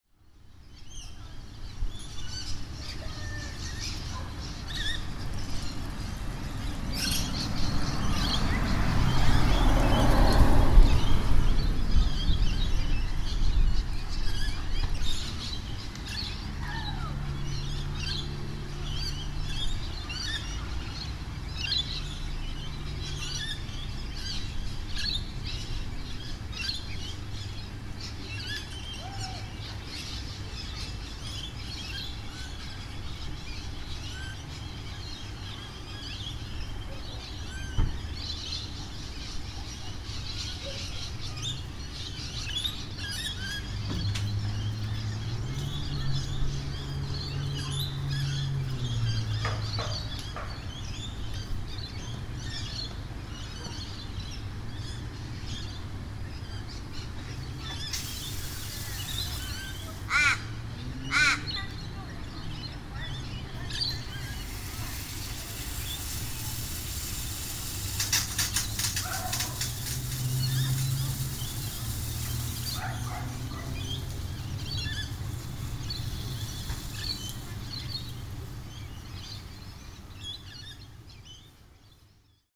Front Suburban Yard In Australia 8pm
Category 🌿 Nature
ambience binaural birds field-recording garden raven suburban watering sound effect free sound royalty free Nature